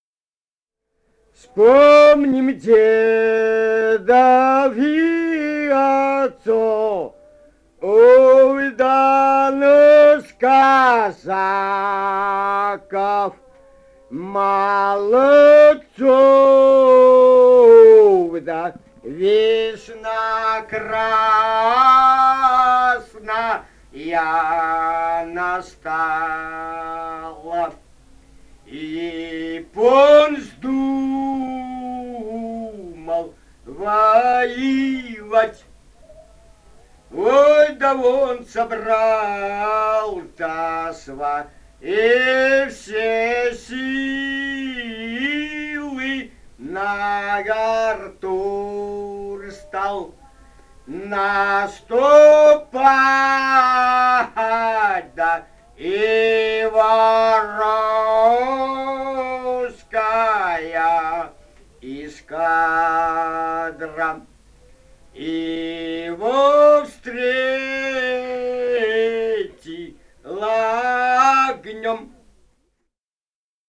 полевые материалы
«Вспомним дедов и отцов» (походная «казачья»).
Бурятия, с. Харацай Закаменского района, 1966 г. И0905-08